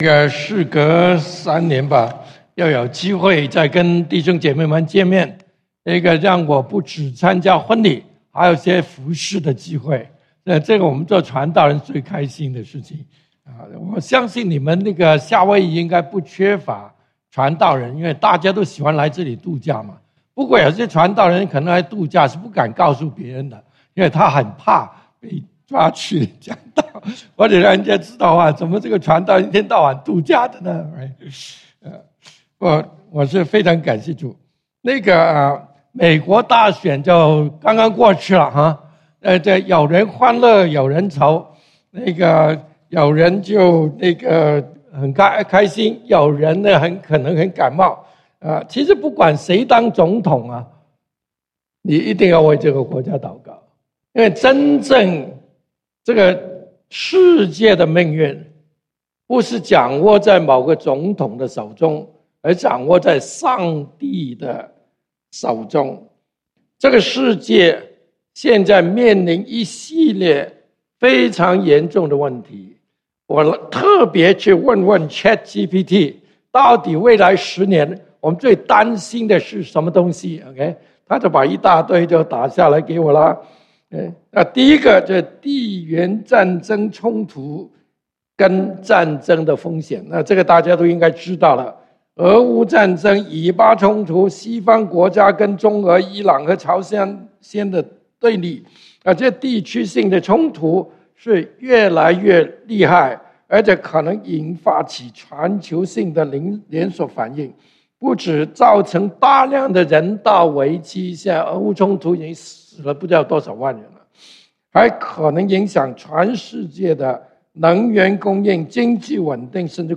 11/10 第一堂崇拜：你也能經驗神蹟 (經文：列王紀上 17:8-16) | External Website | External Website